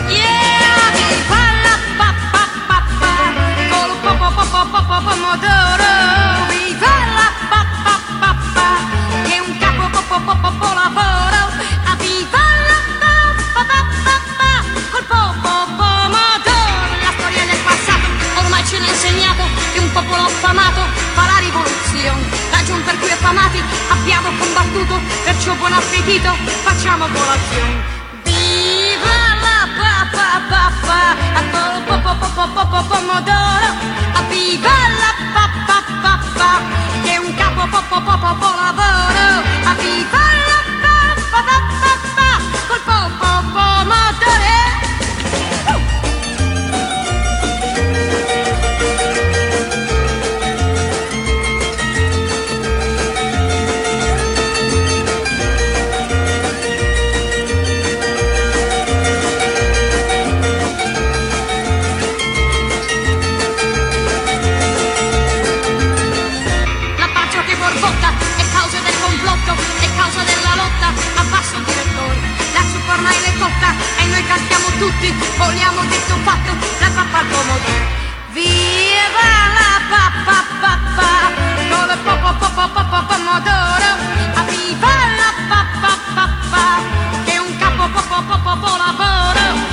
BLUES / RHYTHM & BLUES
シカゴ・ブルース・レジェンド！